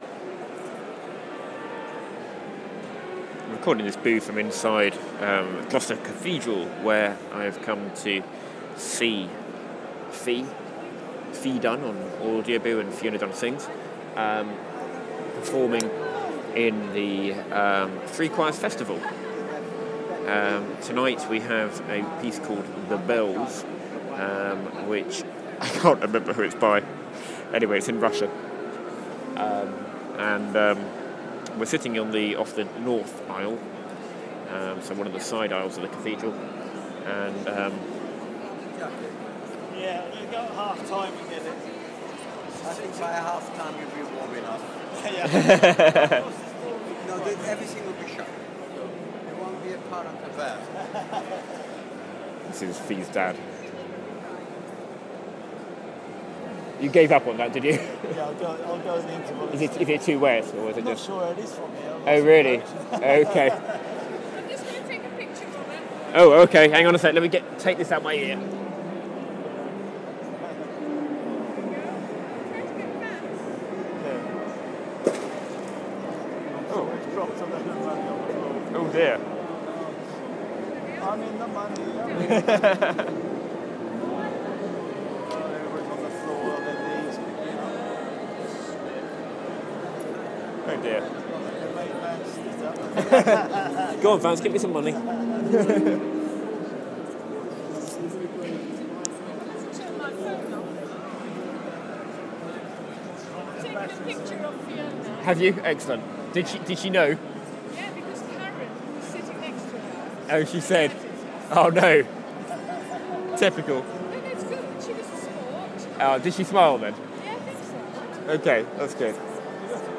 In Gloucester Cathedral for the first concert of the Three Choirs Festival 2013